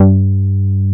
R MOOG G3MP.wav